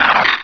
Cri de Mangriff dans Pokémon Rubis et Saphir.